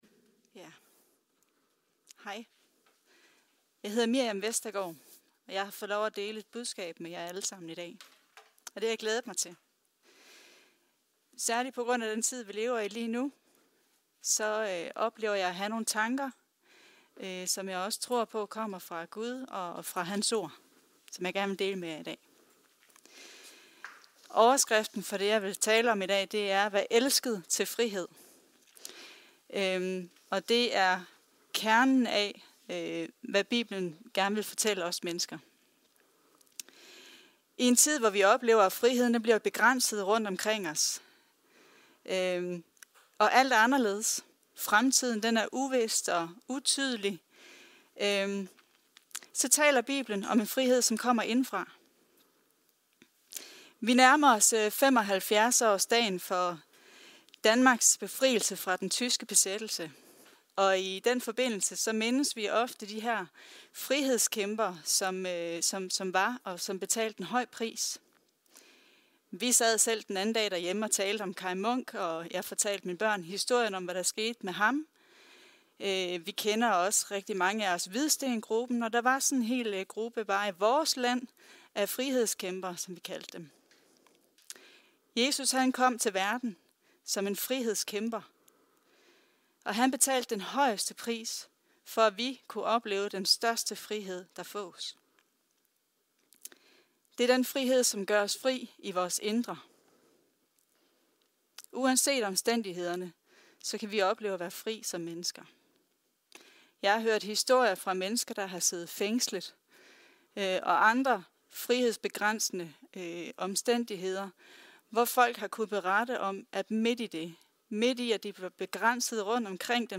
Prædikener
TIDLIGERE PODCASTS Her kan du finder du nogle tidligere taler fra vores gudstjenester.